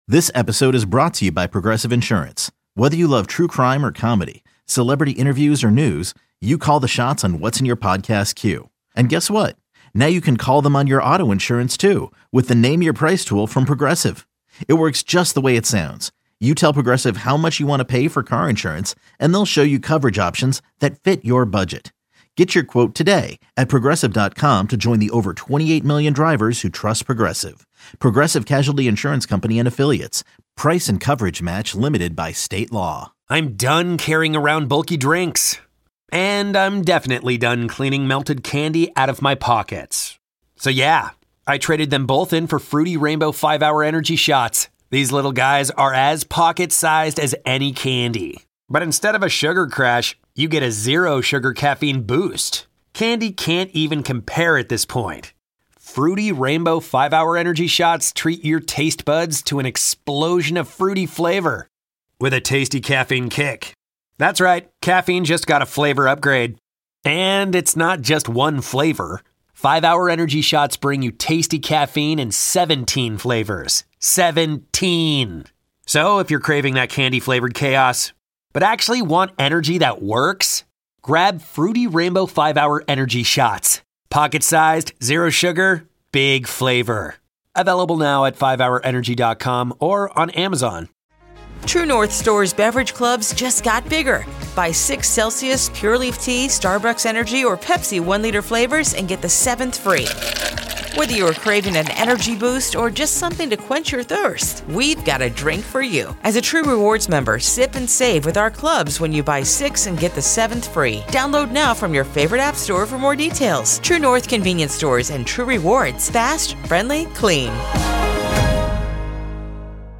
3-6-26 Nine2Noon Interview